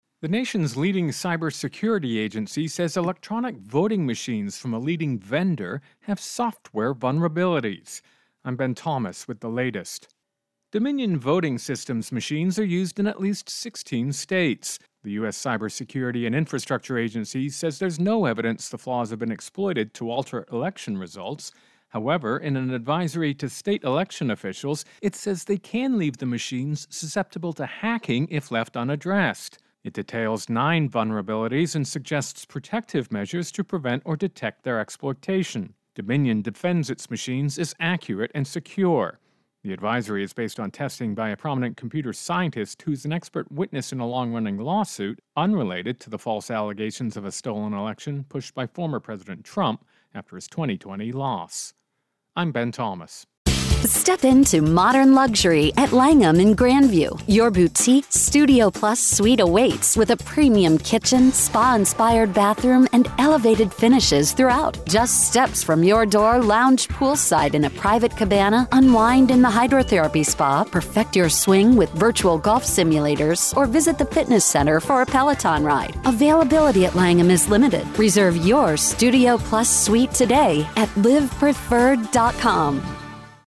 Intro and voicer "Voting Machines"